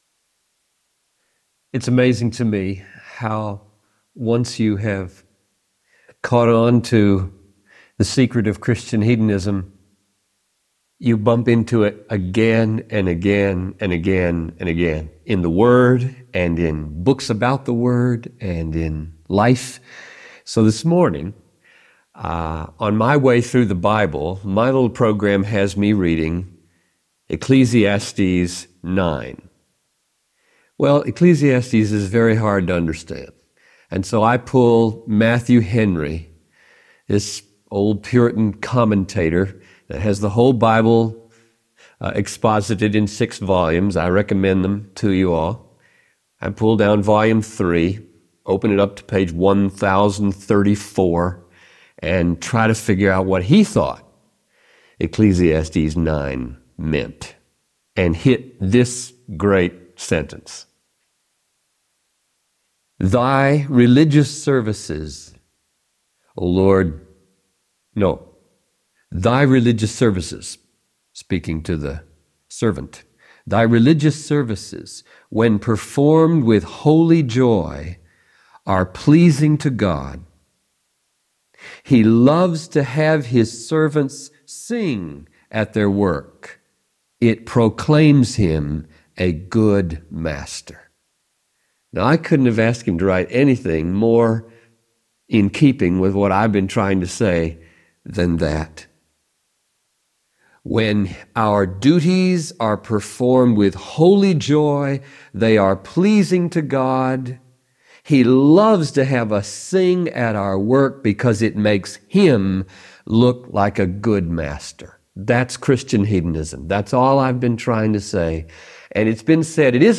Message by John Piper